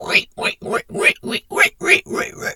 pig_2_hog_seq_04.wav